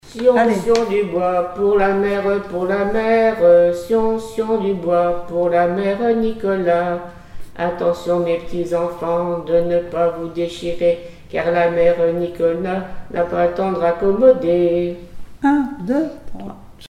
Mémoires et Patrimoines vivants - RaddO est une base de données d'archives iconographiques et sonores.
formulette enfantine : sauteuse
Pièce musicale inédite